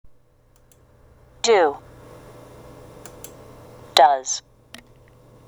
４】esをつけて「ｚ(ズ)」と発音する動詞 (oで終わる単語)